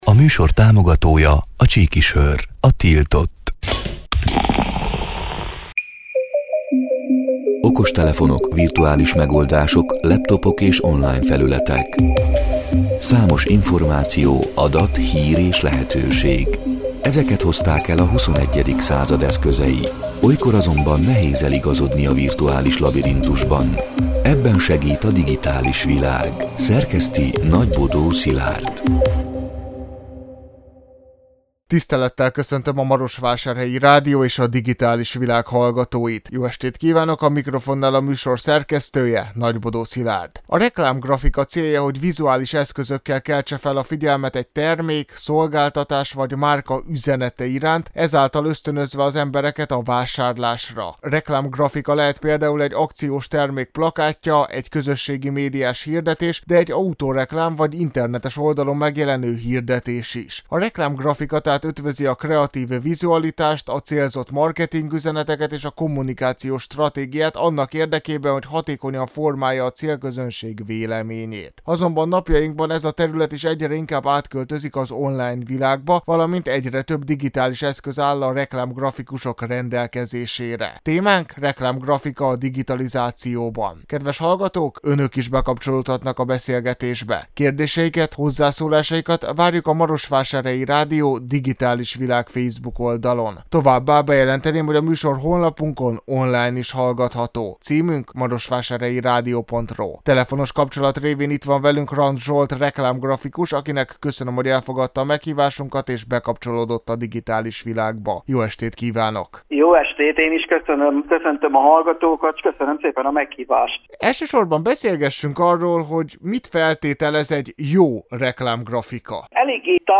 A Marosvásárhelyi Rádió Digitális Világ (elhangzott: 2025. augusztus 19-én, kedden este nyolc órától) c. műsorának hanganyaga: